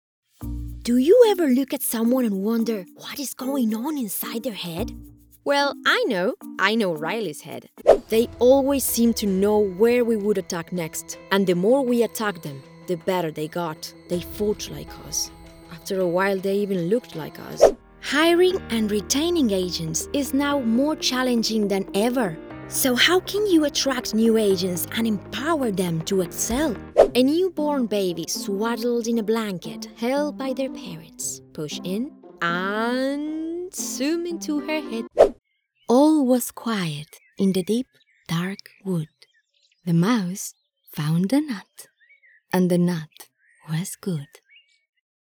English demo
Cambridge University Press - January 2025 - English with Latam Accent